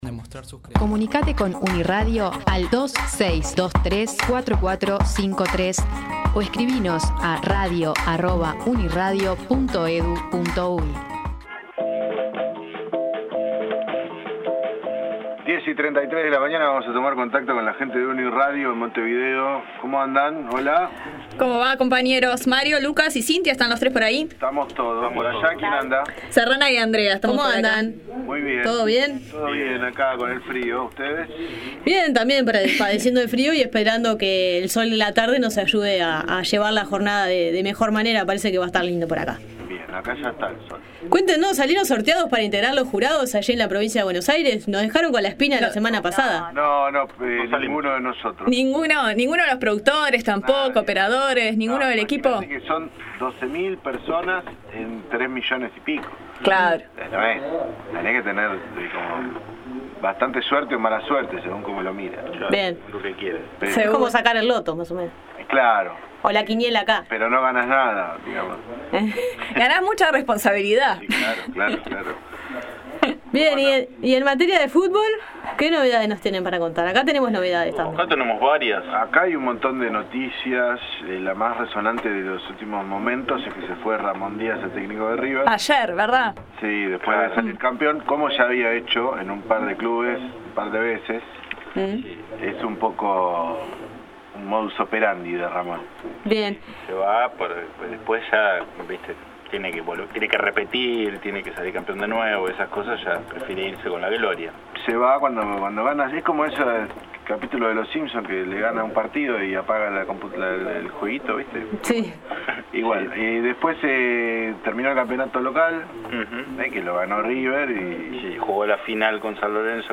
El fútbol como siempre presente: la preocupación de los uruguayos por la lesión de Luis Suarez, la repercusión en Argentina, la renuncia de Ramón Diaz como Director Técnico de River Plate argentino y sus posibles reemplazos, la antesala al Mundial Brasil 2014 en las dos orillas y la definición del campeonato uruguayo, algunos de los temas en el dupléx con la Radio Universidad Nacional de La Plata.